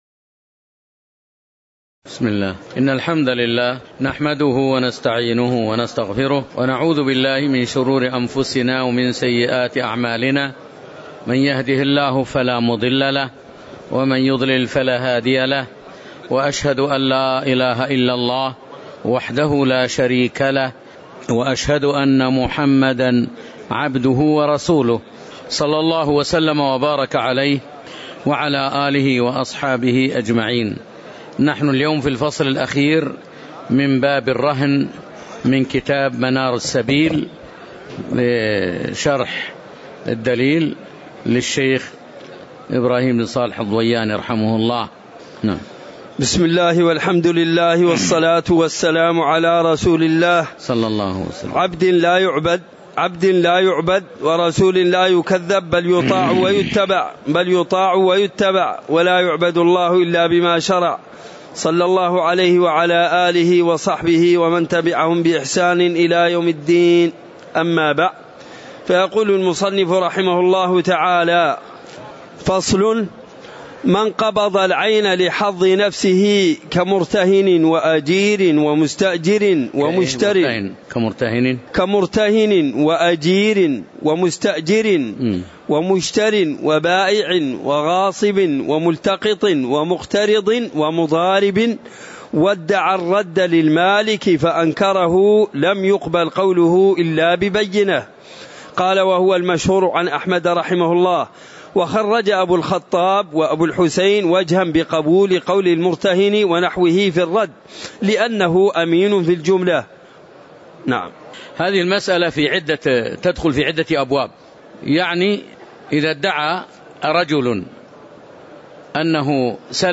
تاريخ النشر ١٤ رجب ١٤٤٠ هـ المكان: المسجد النبوي الشيخ